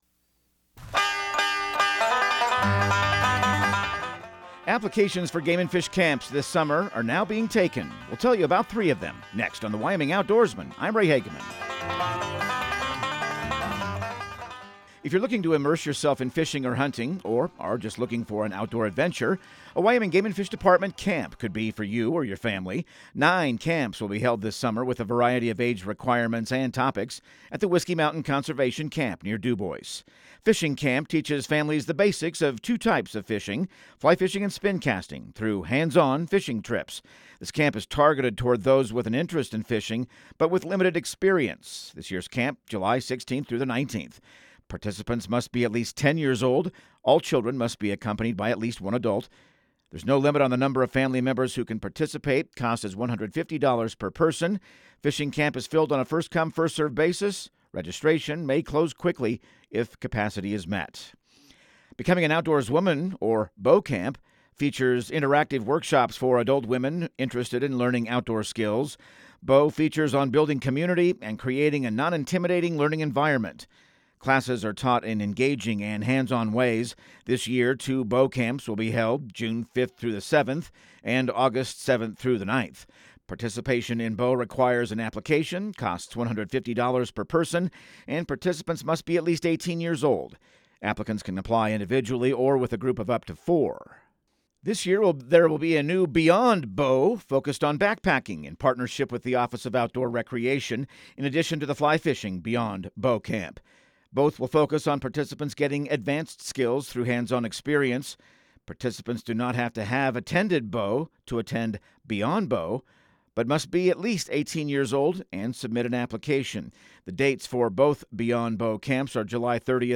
Radio news | Week of January 20